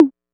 808 TOM 03.wav